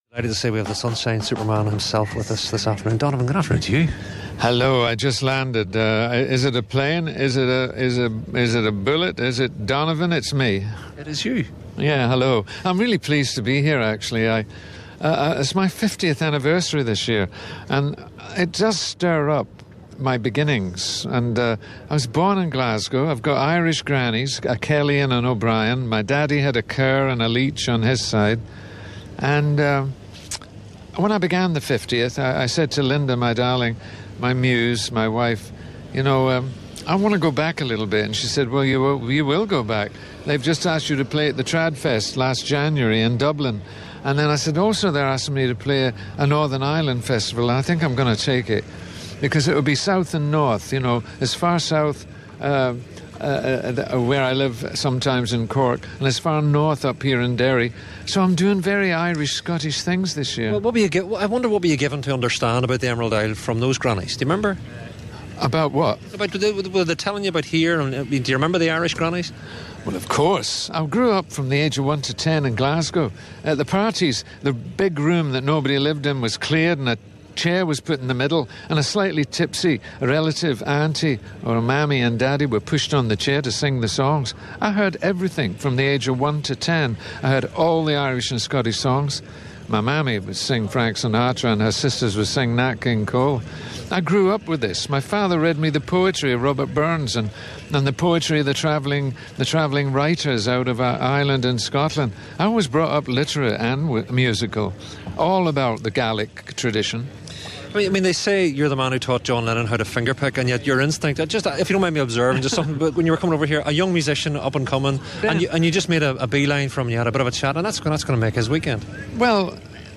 On Friday's we took the show on the road to Stendhal Festival of Art,